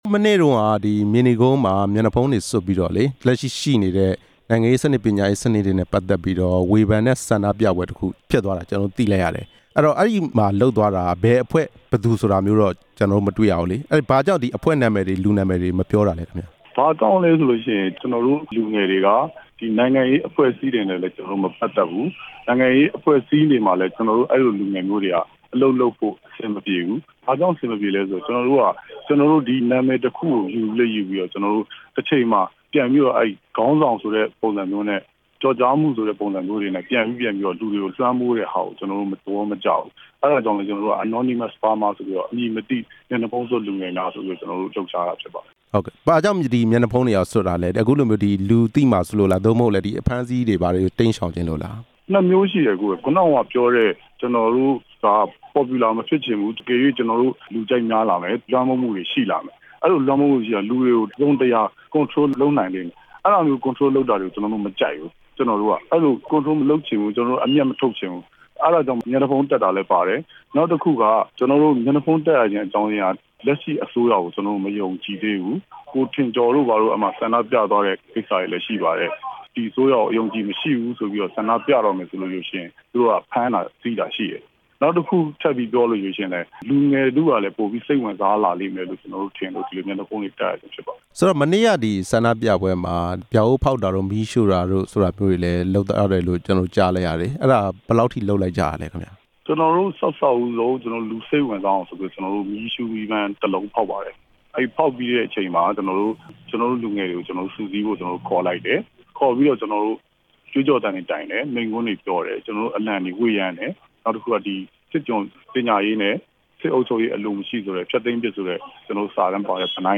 မြေနီကုန်းမှာ မျက်နှာဖုံးစွပ် ဆန္ဒပြသူနဲ့ မေးမြန်းချက်